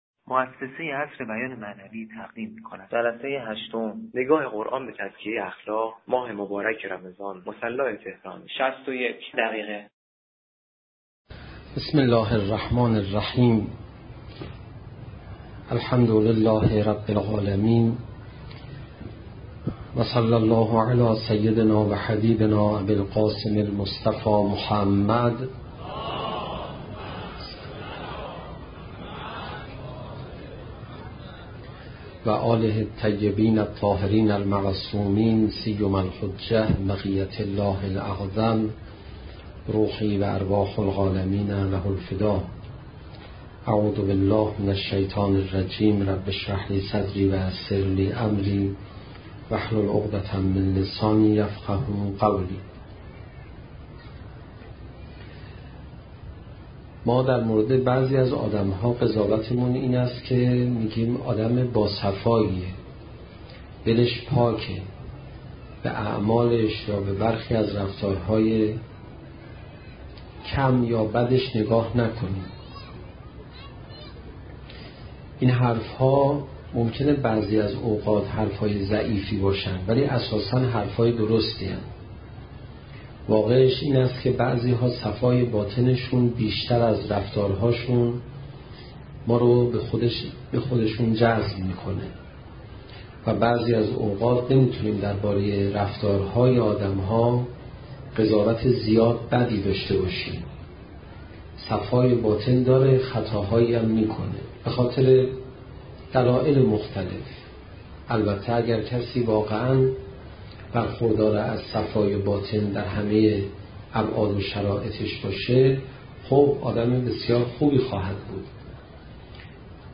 صوت | نگاه قرآن به تزکیه اخلاق (رمضان89 - مصلی تهران - 16جلسه)